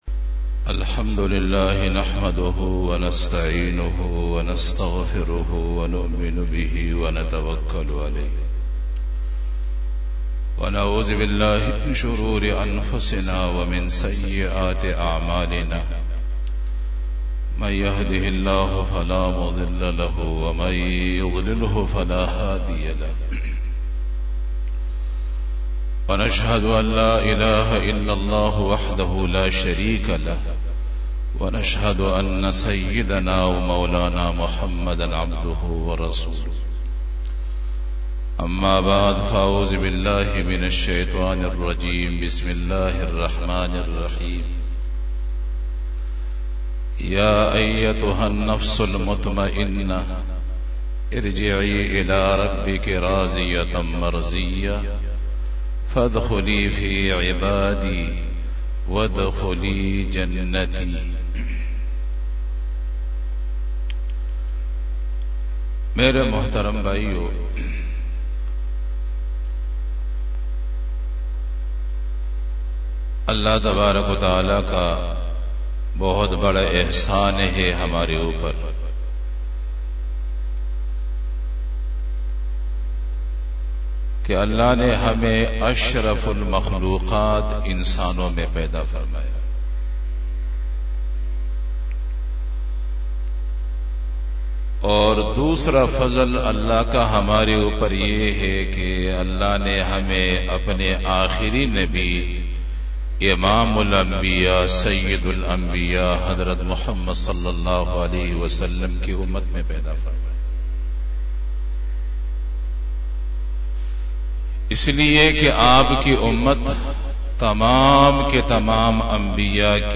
47 BAYAN E JUMA TUL MUBARAK 19 November 2021 (13 Rabi us Sani 1443H)
Khitab-e-Jummah